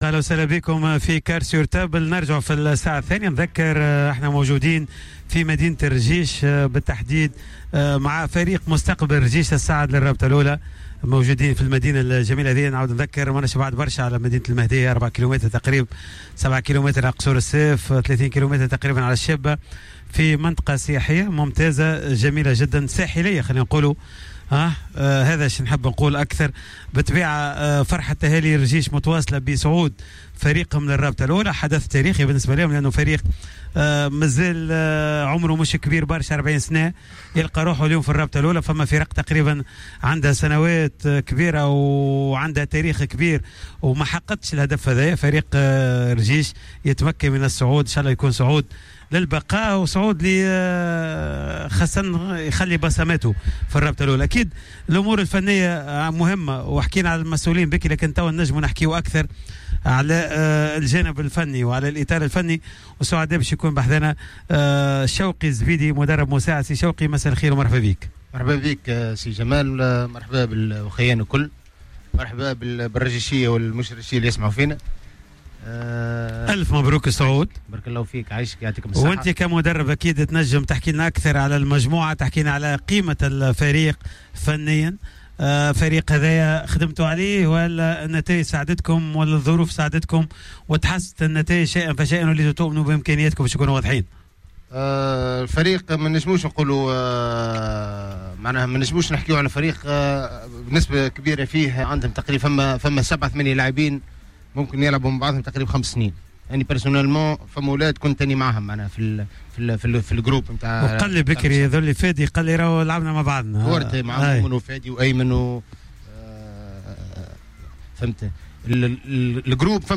خصصت حصة "Cartes sur table" ليوم الثلاثاء 30 جوان 2020 للإحتفال بفريق مستقبل رجيش بصعوده التاريخي للرابطة المحترفة الأولى لكرة القدم و ذلك من خلال استوديو خارجي مباشر من مدينة رجيش.